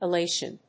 /ɪˈleɪʃən(米国英語)/